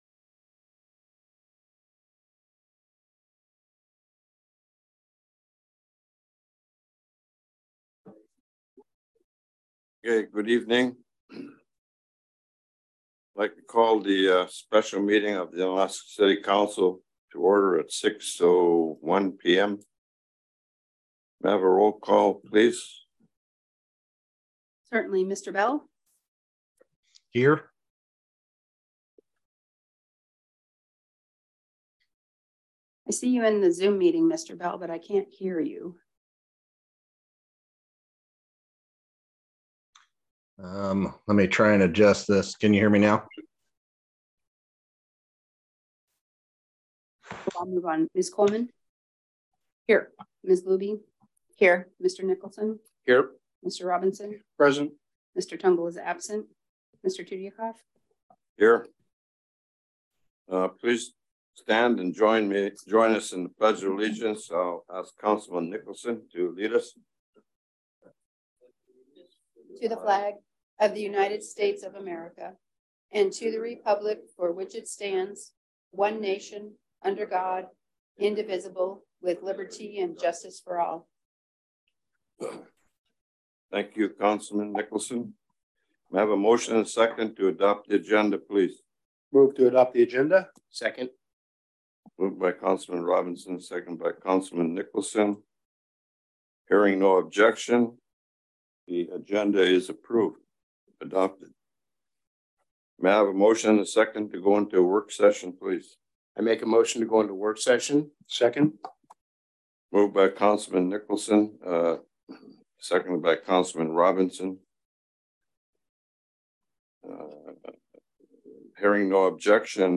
City Council Special Meeting - January 27, 2023 | City of Unalaska - International Port of Dutch Harbor